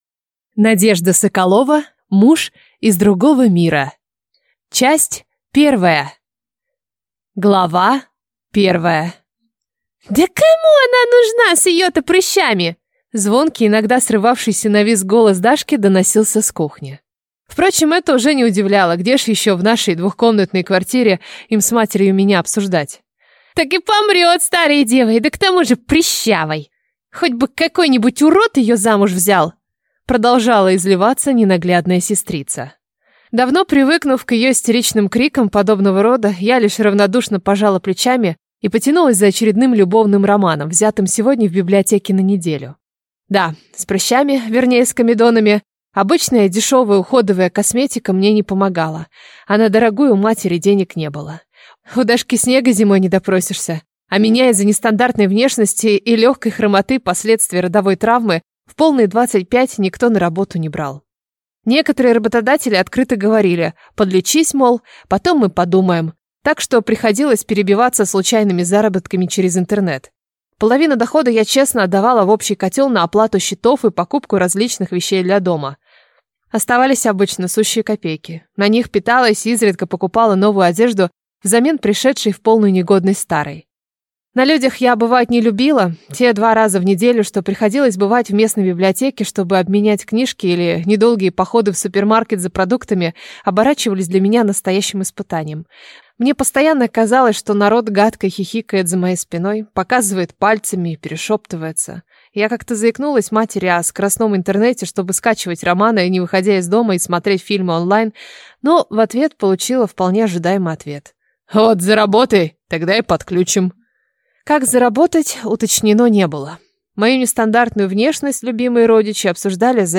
Аудиокнига Муж из другого мира | Библиотека аудиокниг